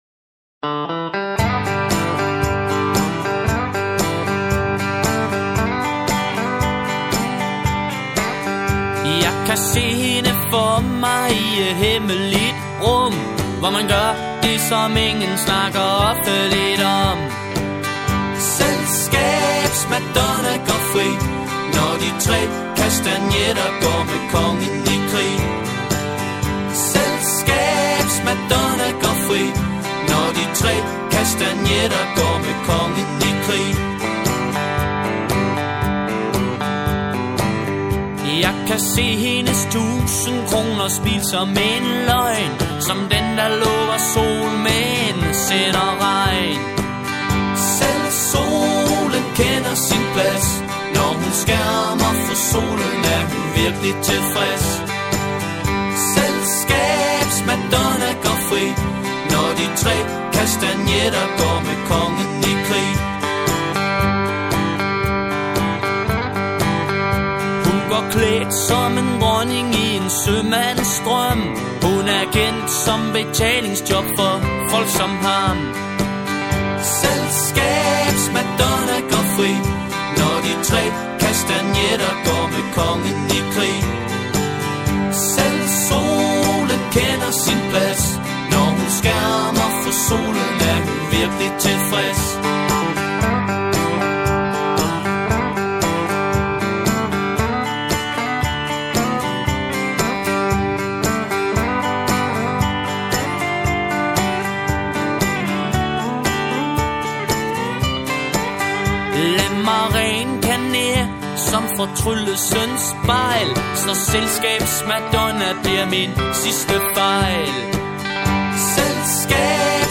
• Coverband
• Unplugged